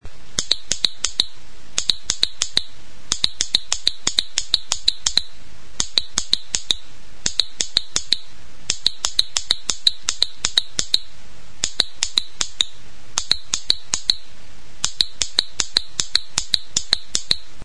Idiófonos -> Punteados / flexible -> Con caja de resonancia
Igela itxura duen brontzezko pieza da. Azpikaldean zanpatzerakoan jiratu eta altzairuzko lamina punteatzen duen palanka dauka.